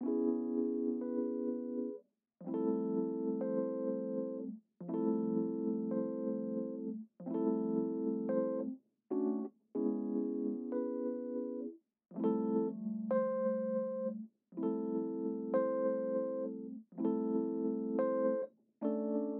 为Lofi和Hiphop制作的快乐音符
标签： 99 bpm Hip Hop Loops Piano Loops 3.26 MB wav Key : B
声道立体声